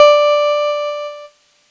Cheese Note 08-D3.wav